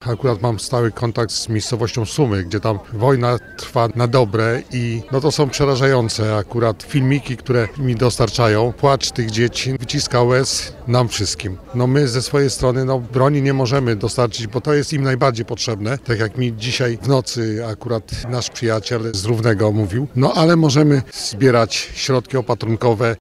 Kazimierz Dolny wspiera Ukrainę. Kilkudziesięciu mieszkańców zgromadziło się w południe przy studni na Dużym Rynku, manifestując solidarność z narodem ukraińskim.